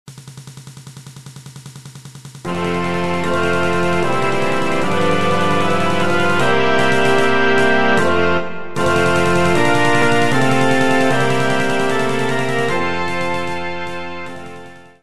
Kościelna